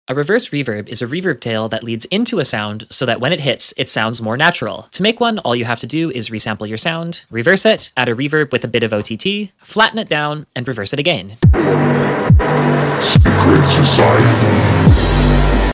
🎧 The FASTEST way to make REVERSE REVERB in 15 seconds! 🎶 Using only Ableton stock plugins—quick, easy, and 🔥 for your tracks!